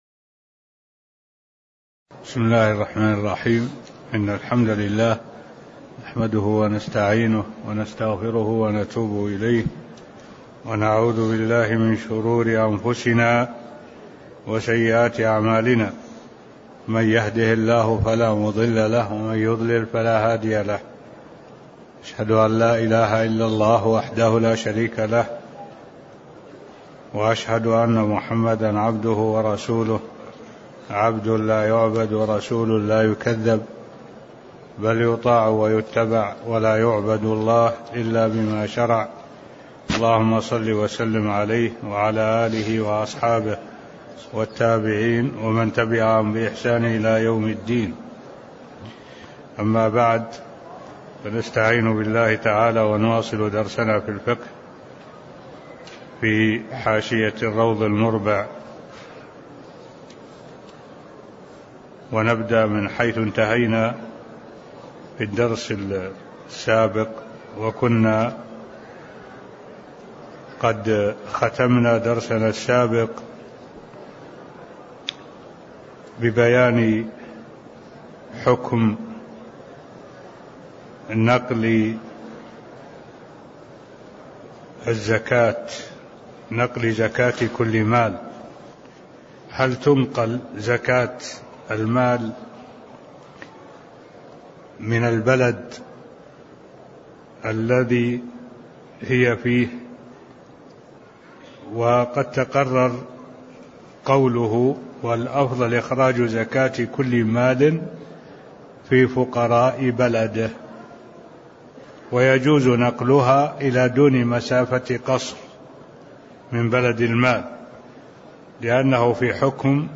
تاريخ النشر ٢٧ جمادى الأولى ١٤٢٩ هـ المكان: المسجد النبوي الشيخ: معالي الشيخ الدكتور صالح بن عبد الله العبود معالي الشيخ الدكتور صالح بن عبد الله العبود نقل الزكاة (012)